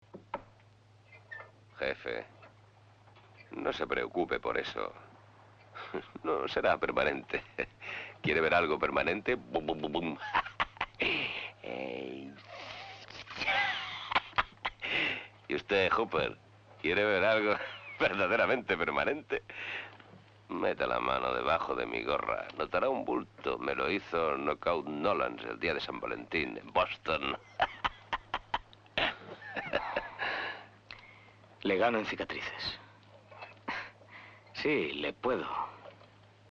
SONIDO (VERSIÓN DOBLADA)
doblaje original de 1975 con el